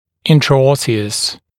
[ˌɪntrə’ɔsɪəs][ˌинтрэ’осиэс]внутрикостный, эндостальный